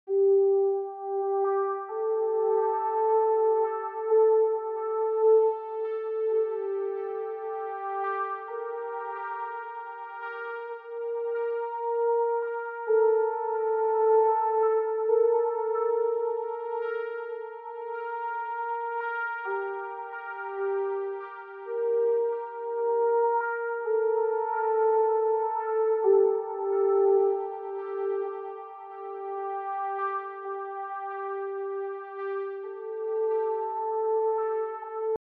Octave 3
Une note sur 6 : Tous les 3 temps
Durée : 2 temps
Cette méthode est redoutable pour créer des nappes éthérées et instables qui pourraient être facilement intégrables dans des compositions électros plus conventionnelles.